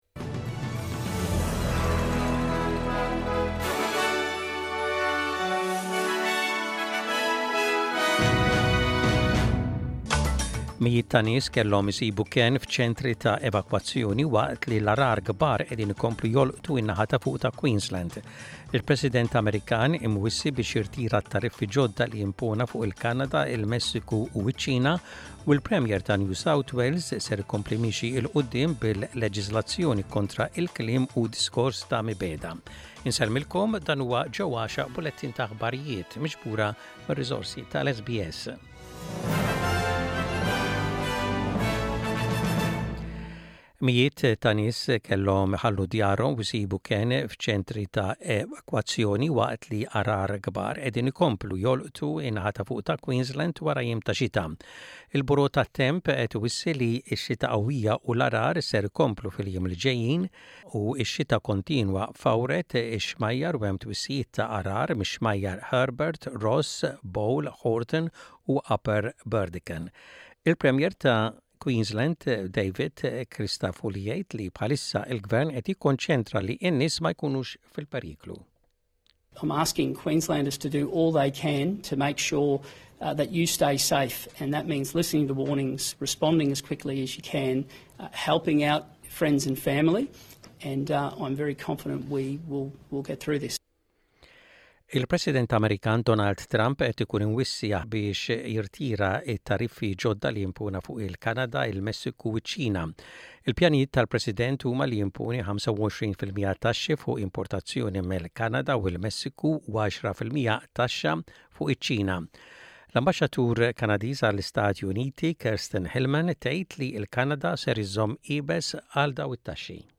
Aħbarijiet bil-Malti: 04.02.25